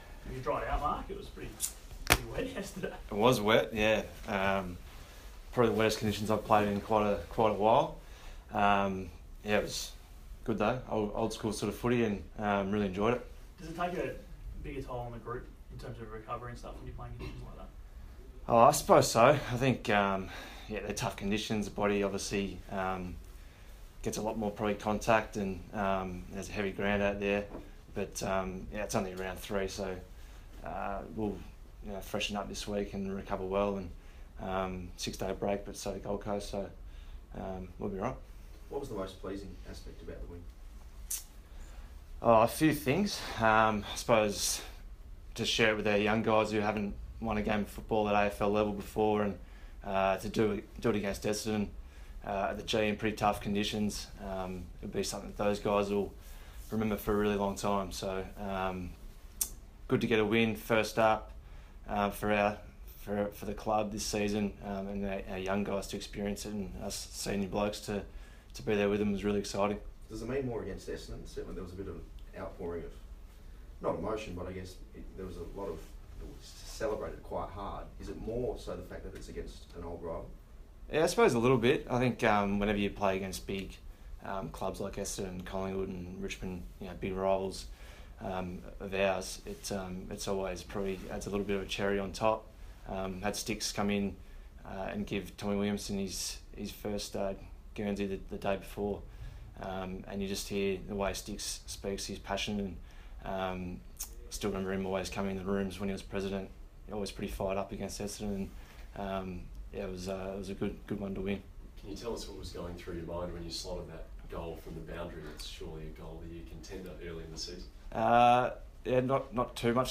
Marc Murphy press conference - April 10
Carlton captain Marc Murphy speaks to the media after the Blues' Round 3 victory over Essendon.